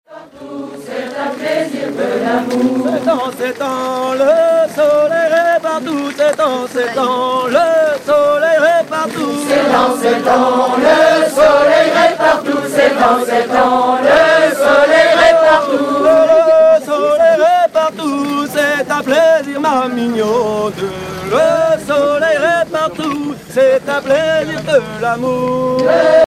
Fonction d'après l'analyste gestuel : à marcher
Genre énumérative
Pièce musicale éditée